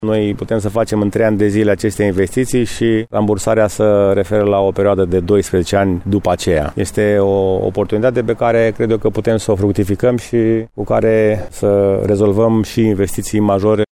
Primarul municipiului Brașov, George Scripcaru: